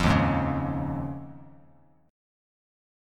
D#7sus2sus4 chord